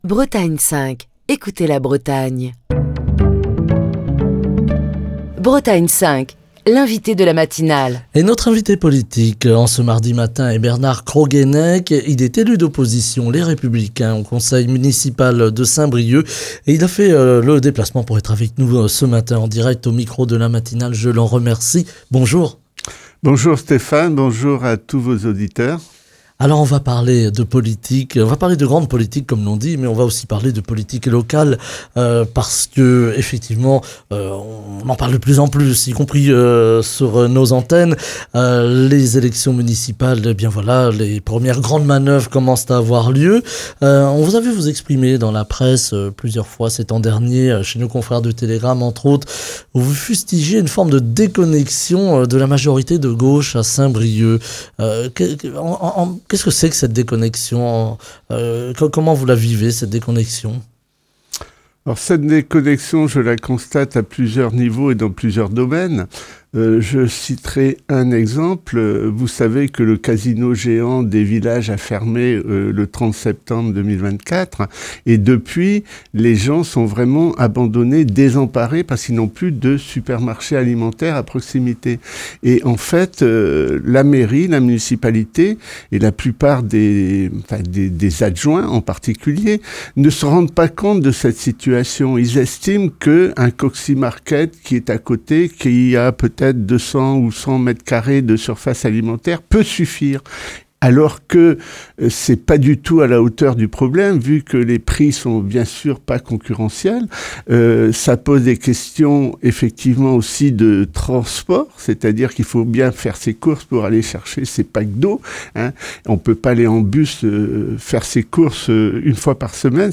Bernard Croguennec, élu d’opposition Les Républicains au conseil municipal de Saint-Brieuc, était l’invité politique de la matinale de Bretagne 5, ce mardi. Au micro de Bretagne 5 Matin, Bernard Croguennec a dressé un large panorama des enjeux locaux briochins, en pointant ce qu’il considère comme une forme de déconnexion entre les élus de la majorité municipale et les attentes des habitants.